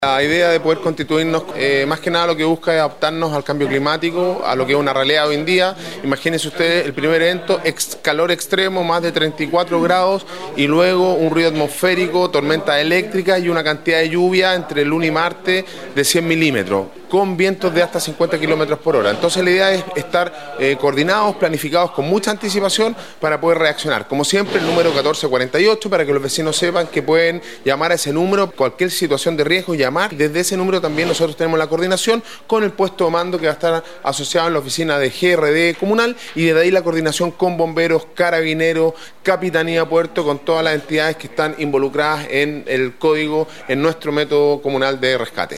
Alcalde-Sebastian-Alvarez-detalla-los-eventos-meteorologicos-en-la-zona.mp3